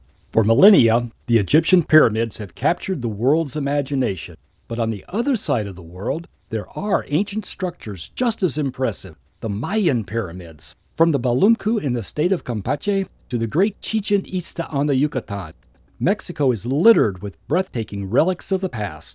Professional, Guy next door, Warm Male Voice Over Talent
Male
English (North American)
Adult (30-50), Older Sound (50+)
Explainer Videos
All our voice actors have professional broadcast quality recording studios.